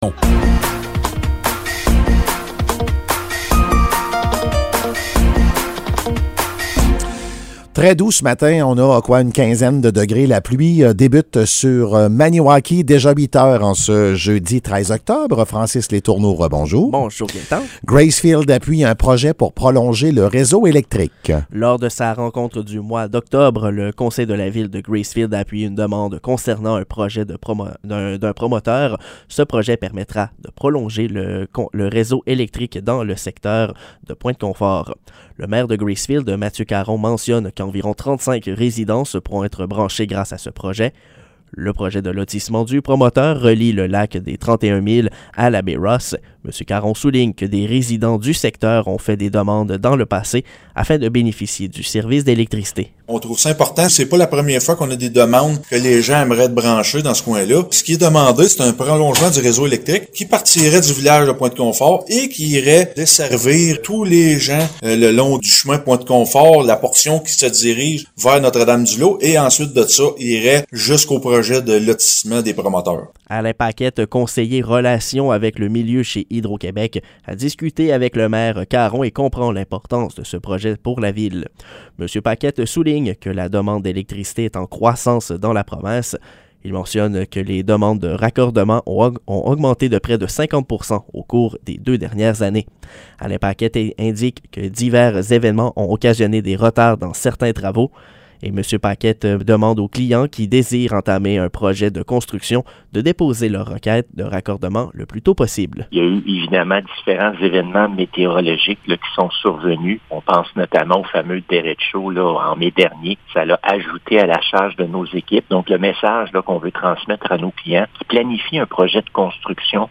Nouvelles locales - 13 octobre 2022 - 8 h